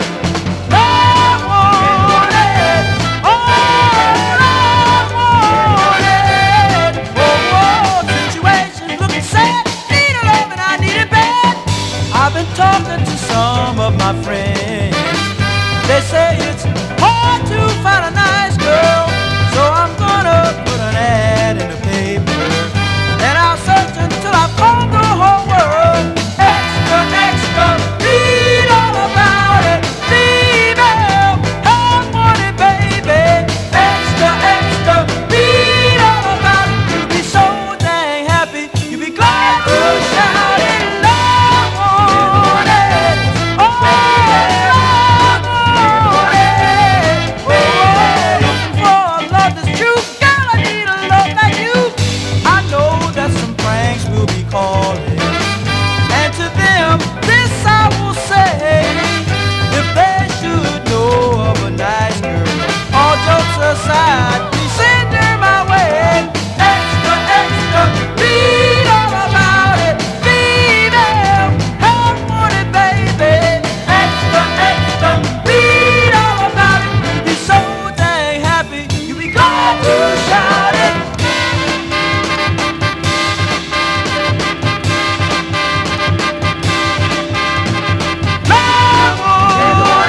SOUL / SOUL / 60'S / RHYTHM & BLUES / VOCAL / OLDIES
ハッピーなリズム＆ブルース・デュエット名作！